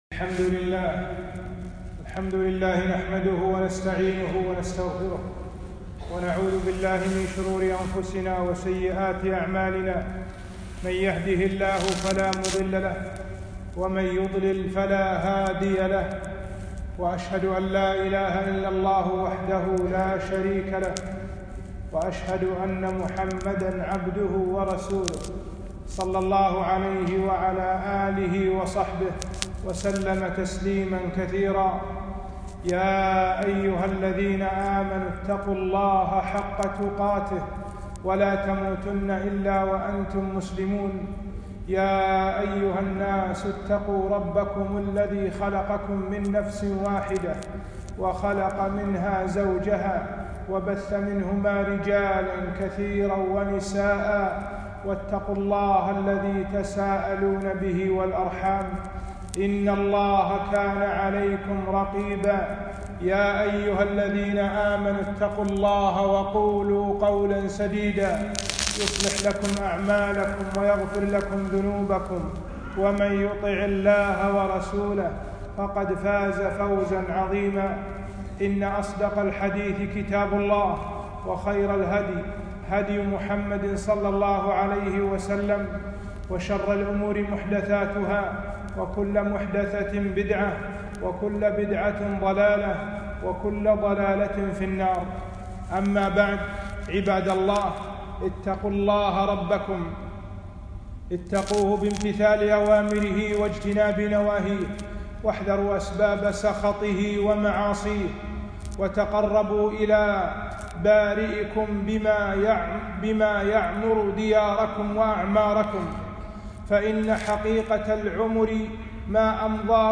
خطبة - صلة الرحم